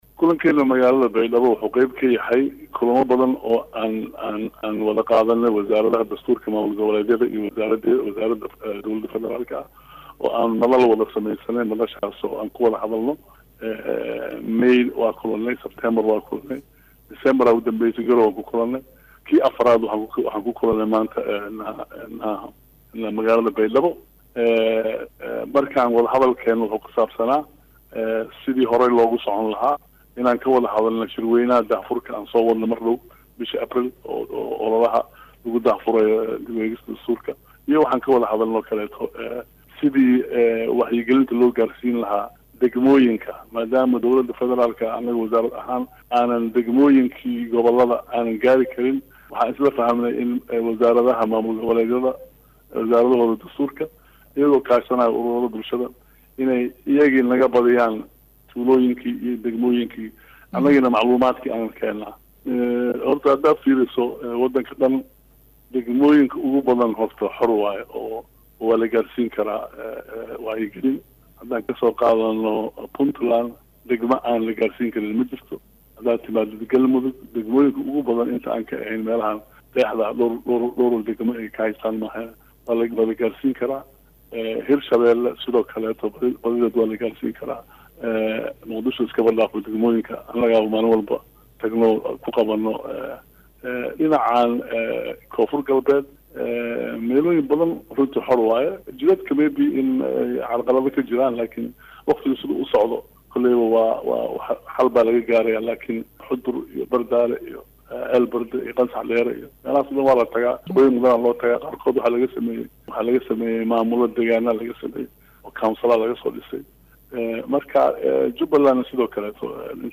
wasiir-XOOSH.mp3